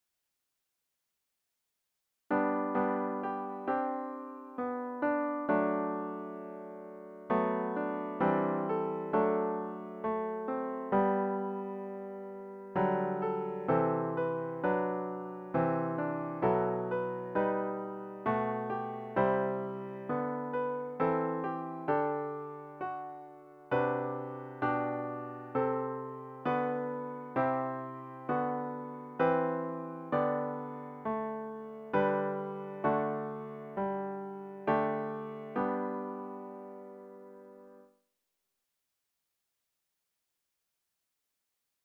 About the Hymn